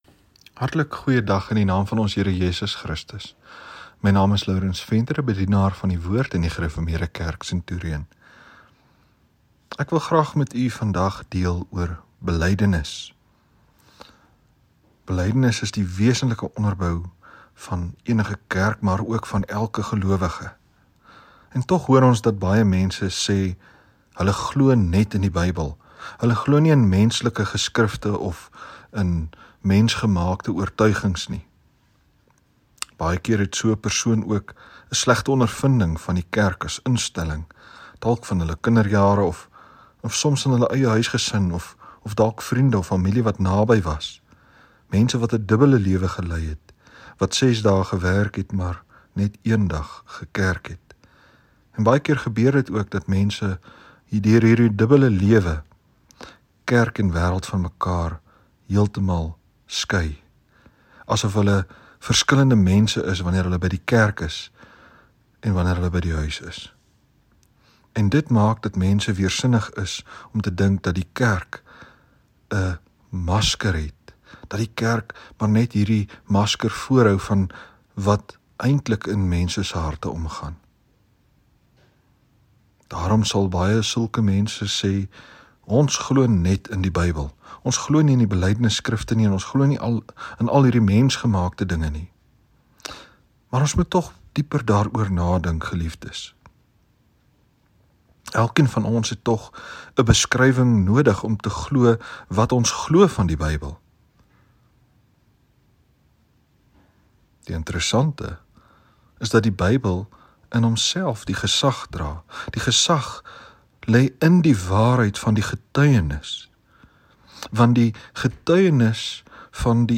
Oordenking 20 Augustus